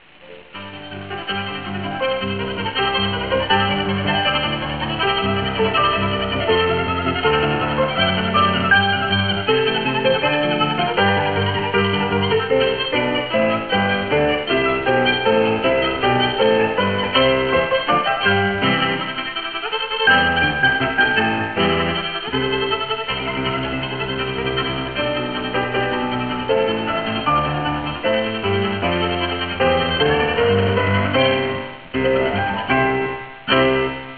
壮大なモンゴルをイメージさせるタイトル曲、
馬頭琴の魅力たっぷりのCDです。